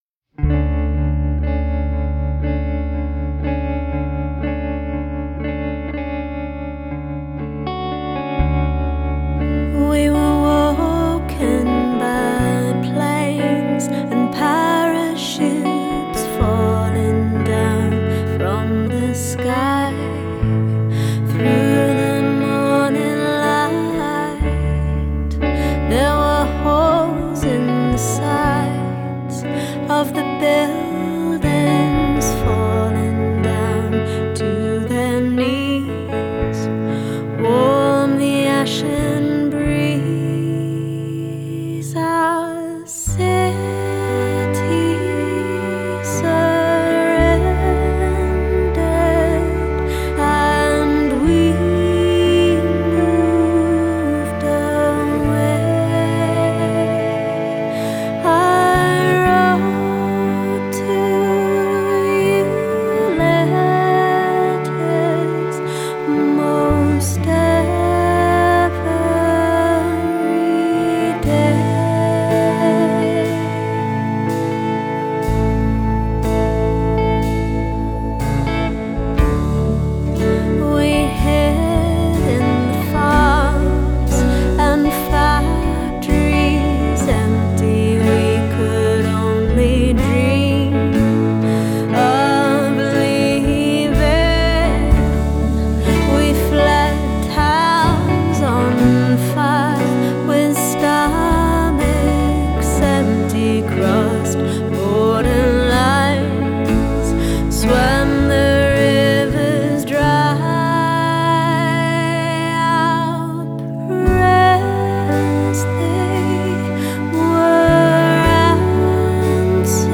More electric than before, but as warm and deep as ever.